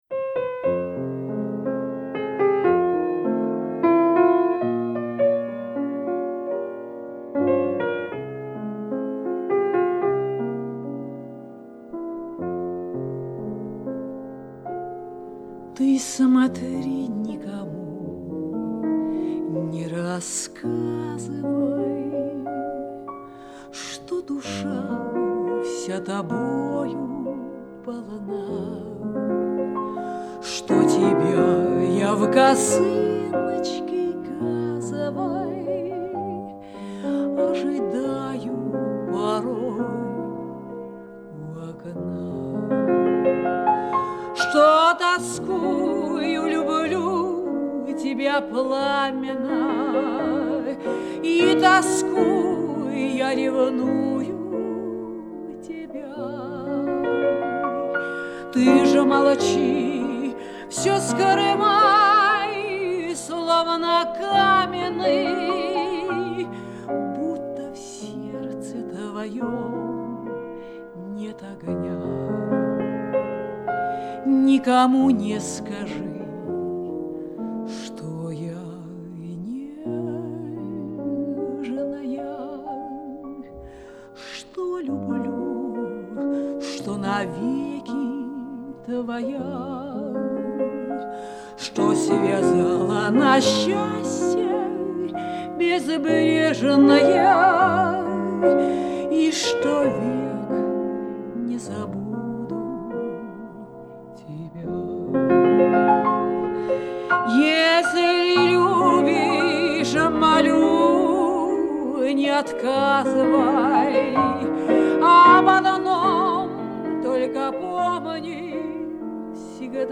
Романс